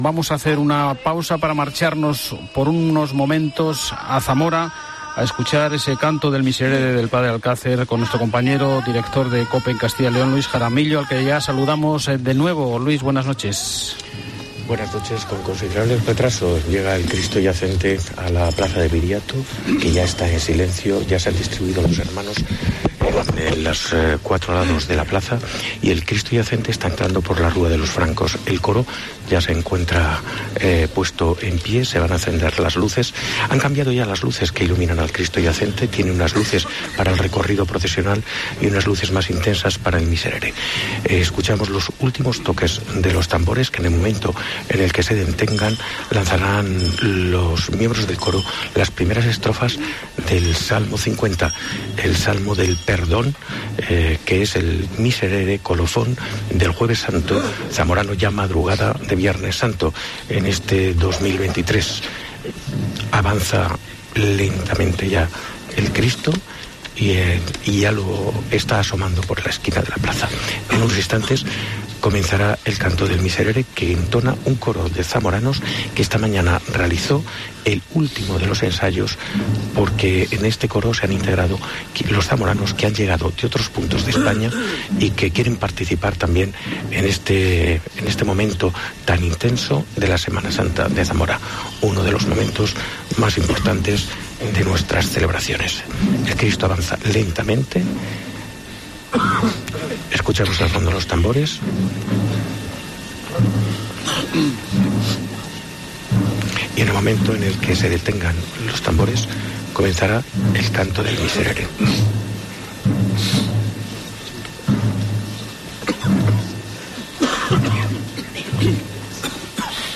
RETRANSMISION DEL MISERERE 2023